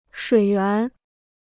水源 (shuǐ yuán): water source; water supply.